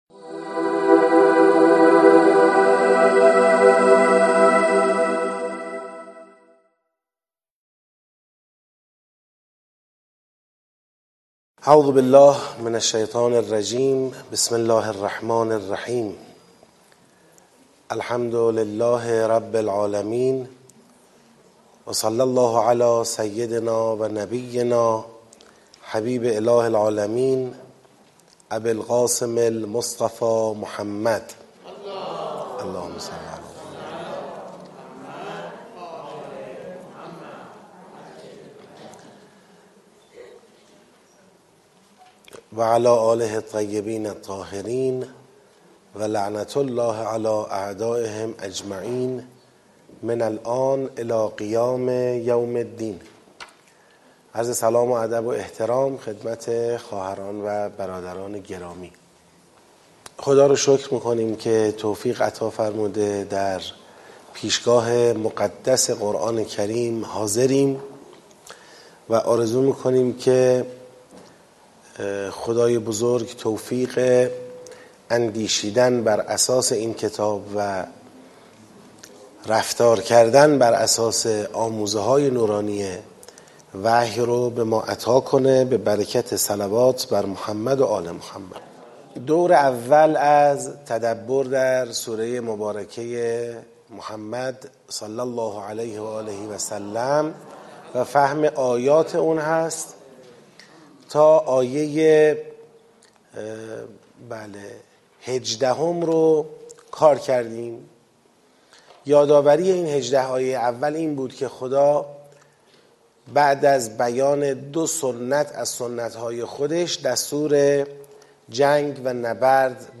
ترم ششم این دوره از مهر ۱۴۰۱ در سالن شهید آوینی مسجد حضرت ولیعصر (عج) شهرک شهید محلاتی آغاز شد و طی ۱۲ هفته برگزار خواهد شد.